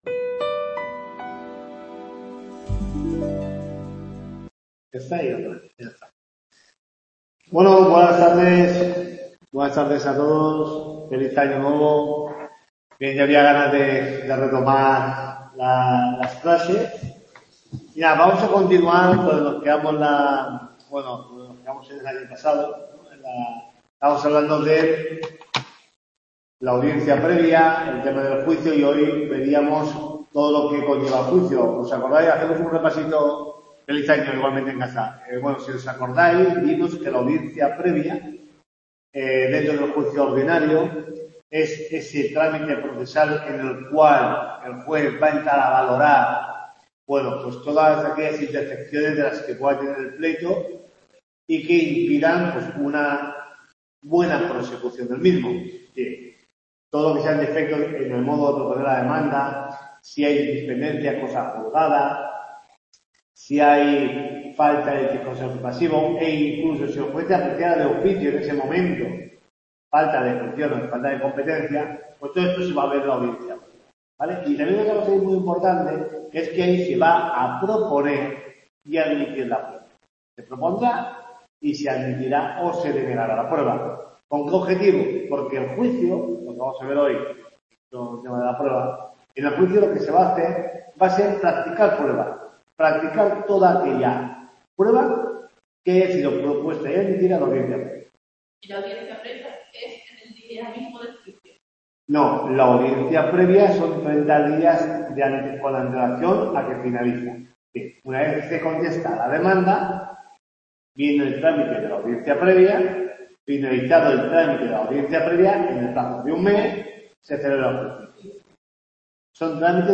TUTORIA 11